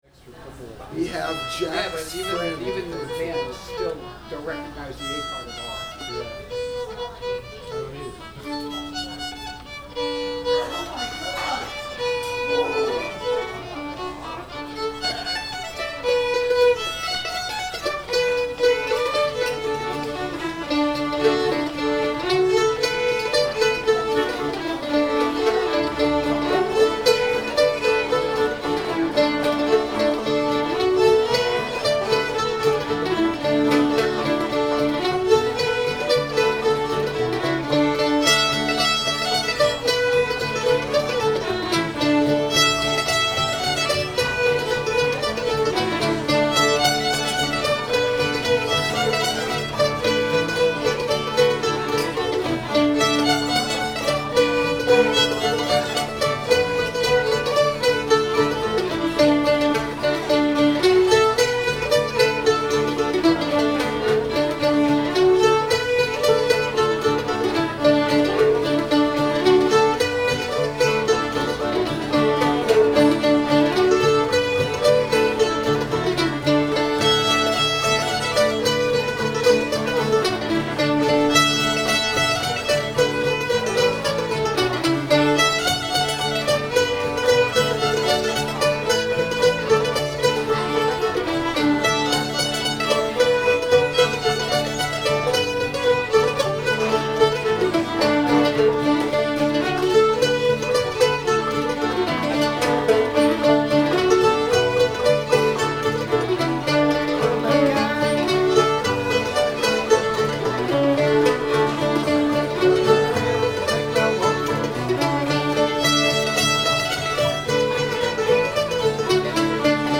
cumberland gap [D]